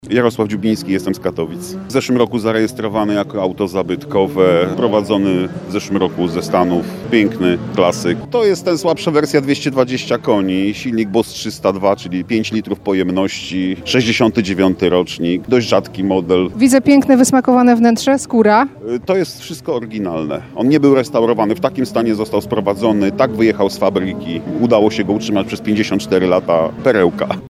Ryk silników, pisk opon i maszyny takie, że dech zapiera. W hali pod Dębowcem w Bielsku-Białej trwa Moto Show 2023 – największa impreza motoryzacyjna w tej części Europy.